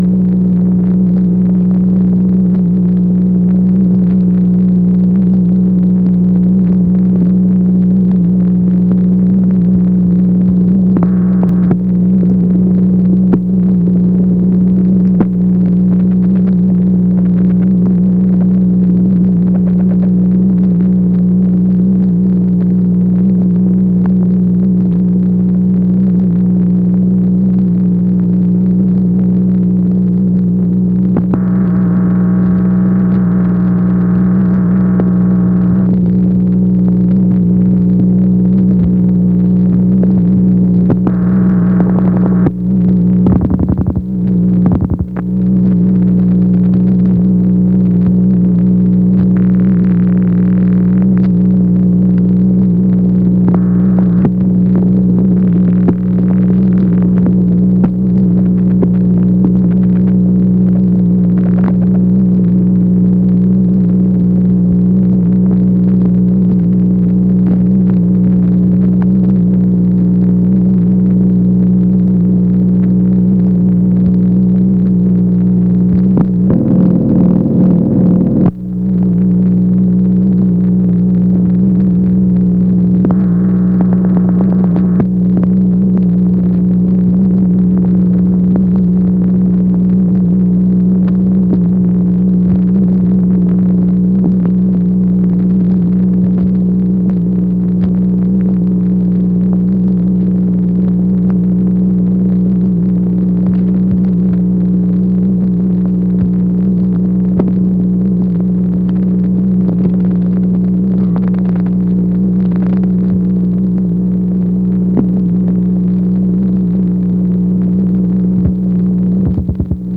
MACHINE NOISE, February 20, 1964